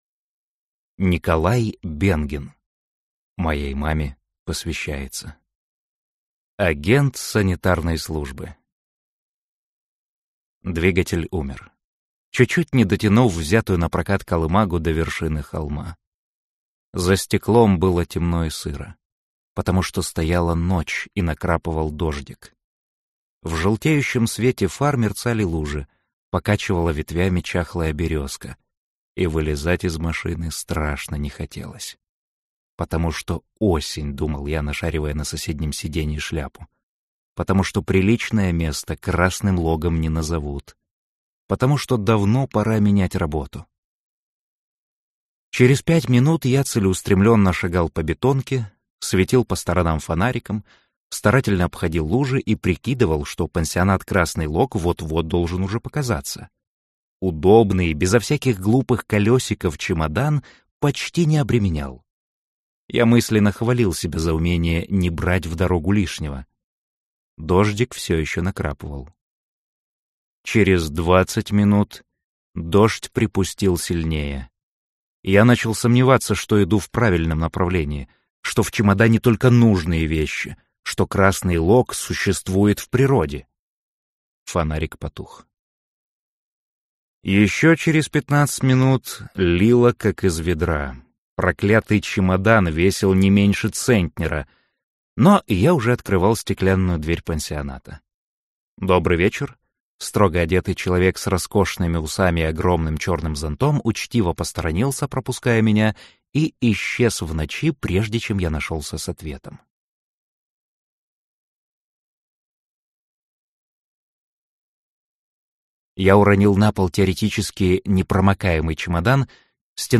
Аудиокнига Агент санитарной службы | Библиотека аудиокниг